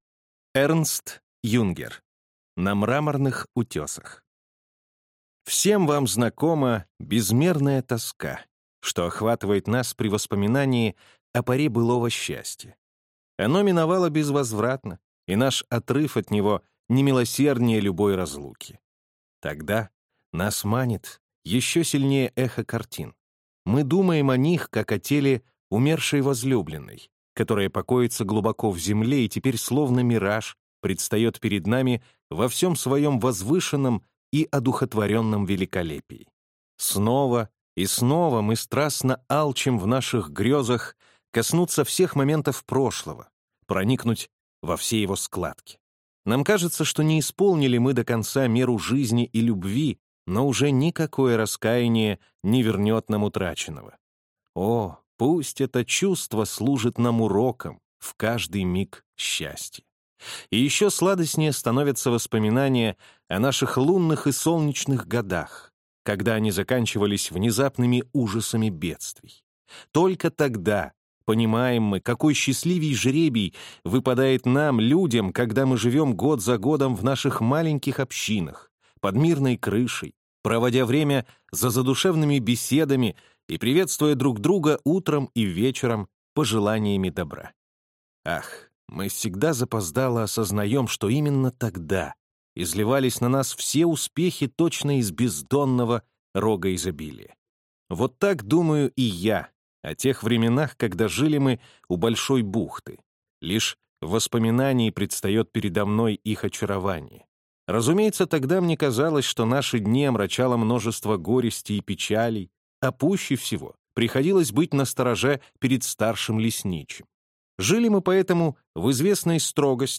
Аудиокнига На мраморных утесах | Библиотека аудиокниг